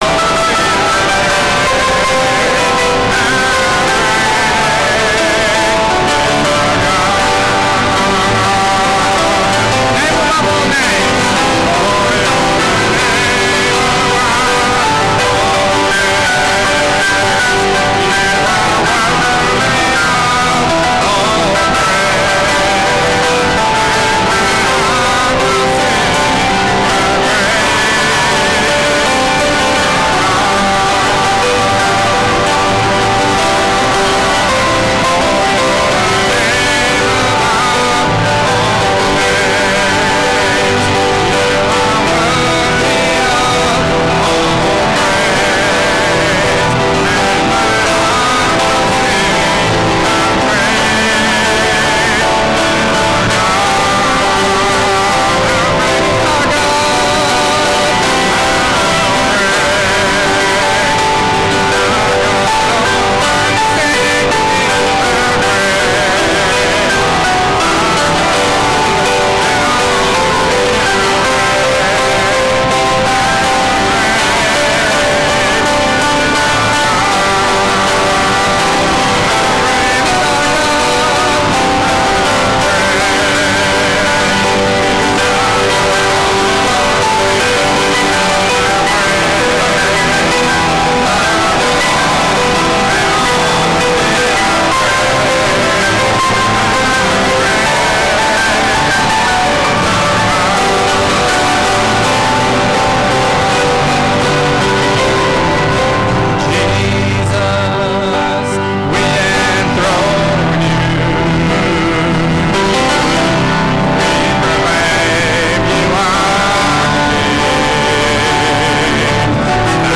From Meetings: "Bowens Mill Conv Mar 2024"